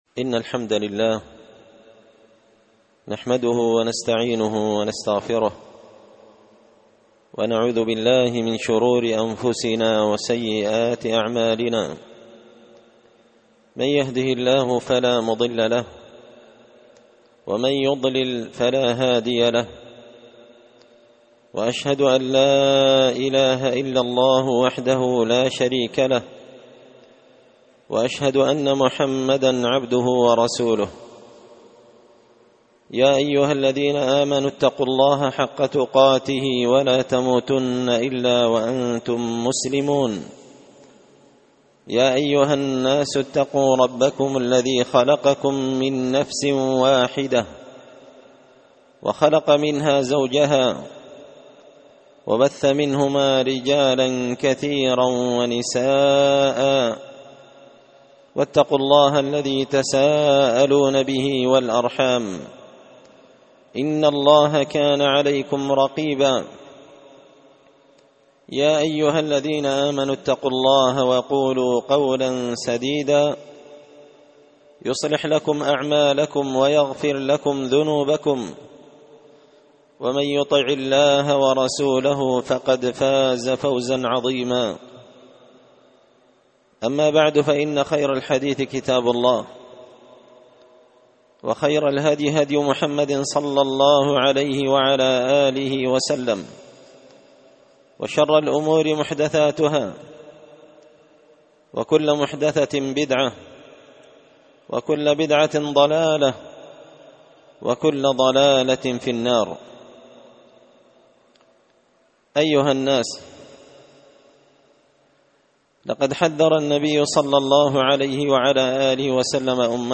خطبة جمعة بعنوان
دار الحديث بمسجد الفرقان ـ قشن ـ المهرة ـ اليمن